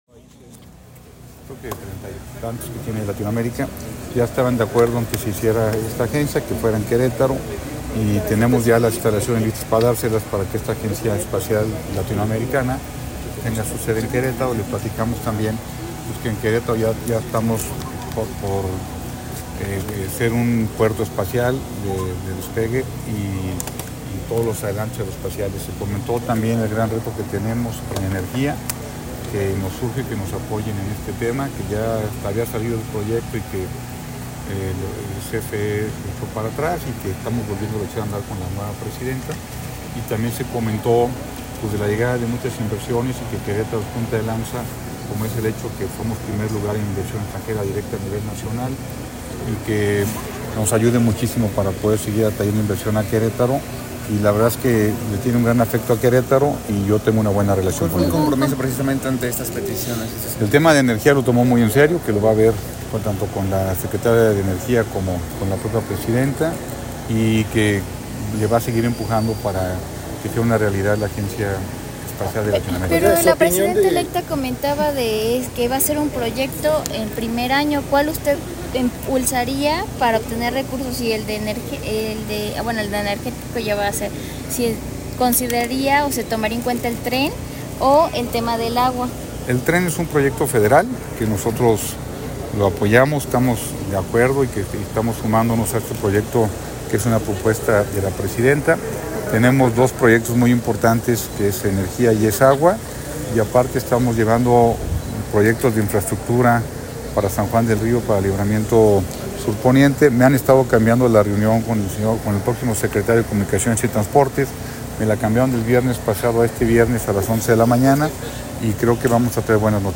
Entrevista Gobernador Kuri. Reunión con Ebrard y más